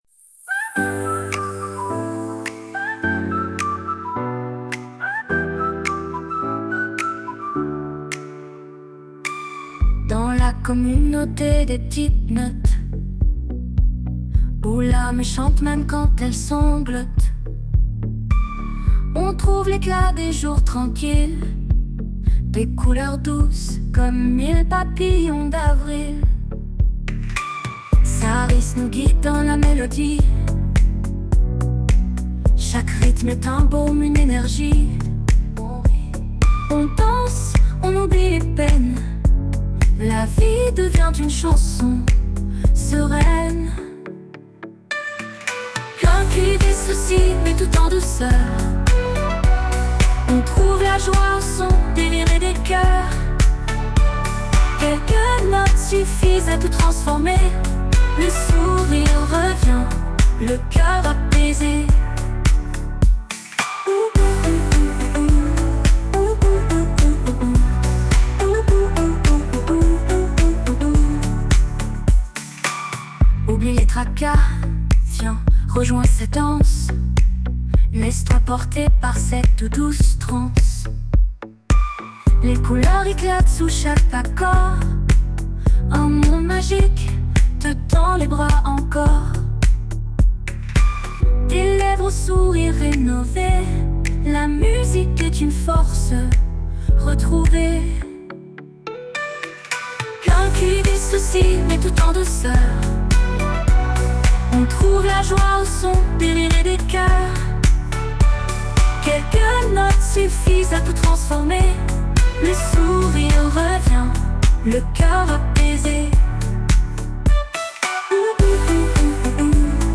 … et j’ai découvert SUNO… Un site où on crée de la musique avec l’I.A. en lui donnant un prompt, ou des lyrics.